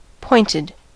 pointed: Wikimedia Commons US English Pronunciations
En-us-pointed.WAV